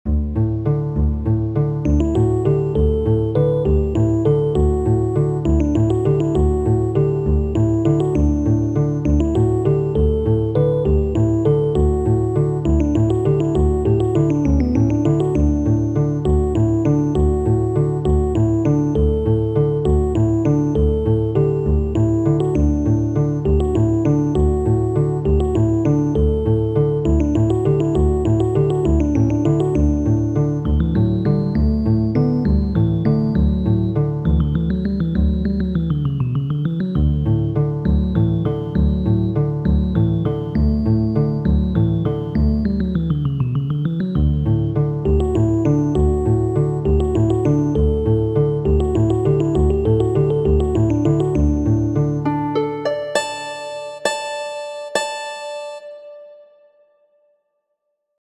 Saltarello, danco komponita de Vicenzo Galilei, patro de la fama sciencisto.